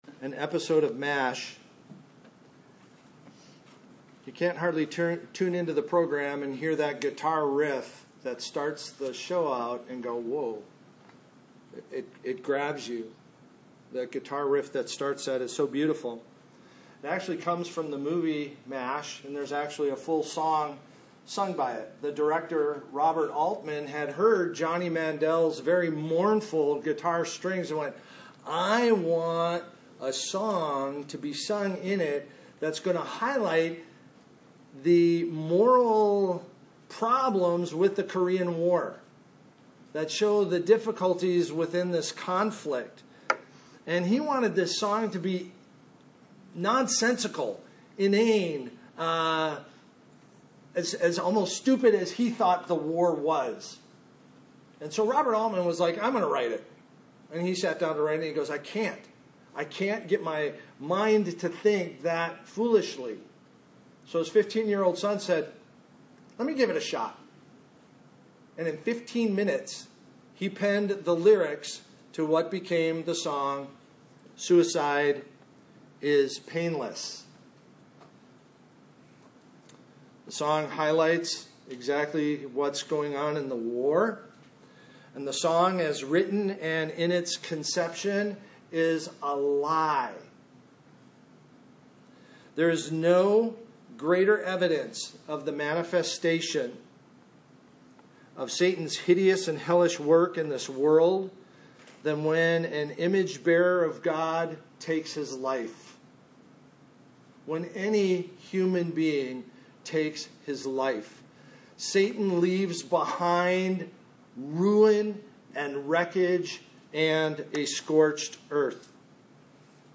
2025 Suicide Is Never Painless Preacher